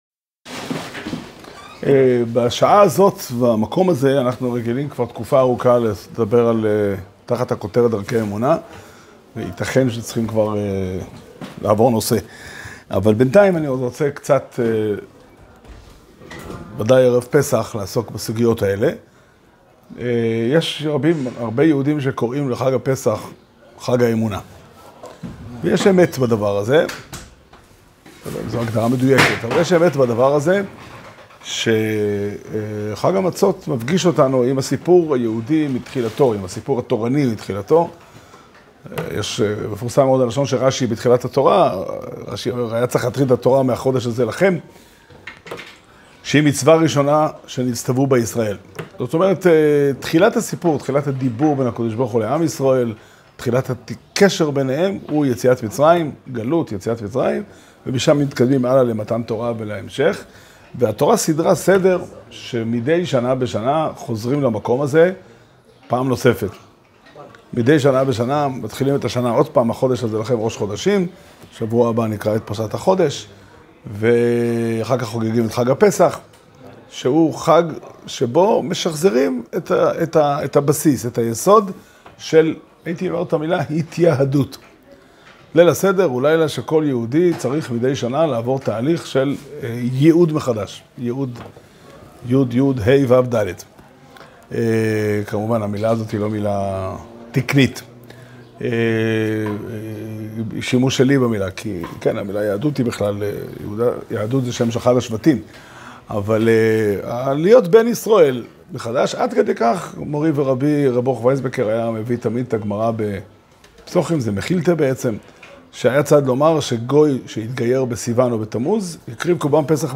שיעור שנמסר בבית המדרש פתחי עולם בתאריך י"ט אדר אדר תשפ"ה